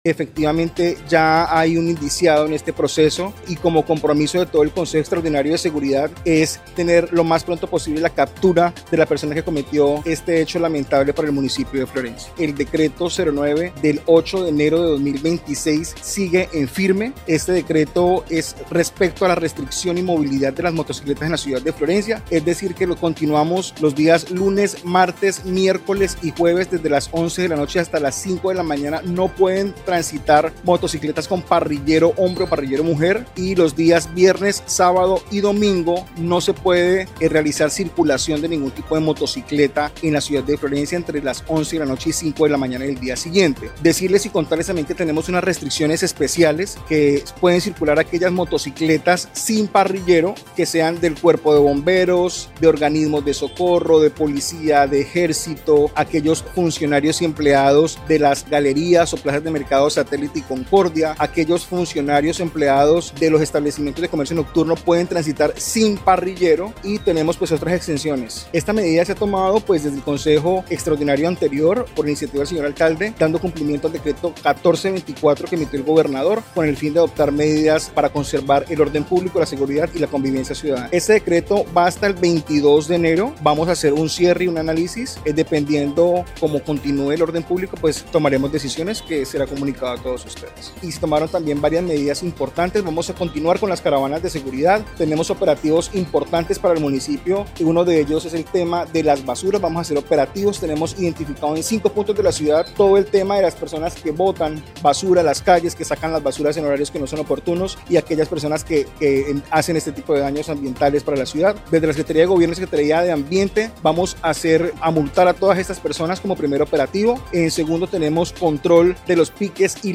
Así lo dio a conocer el secretario de gobierno municipal Neomeyer Cuéllar Castro, al indicar que, tras el más reciente consejo de seguridad extraordinario, los organismos de investigación presentes en la ciudad, tienen individualizado a una persona por su participación en el hecho.